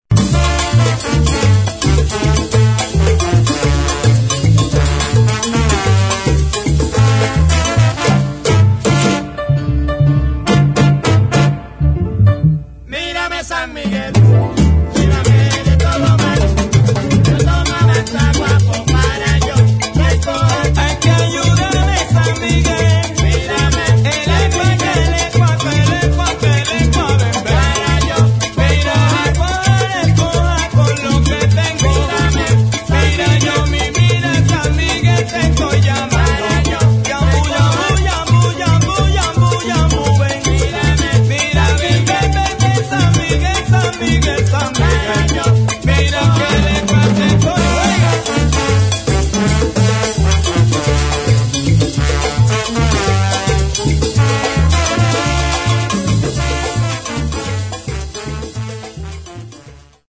[ FUNK / LATIN ]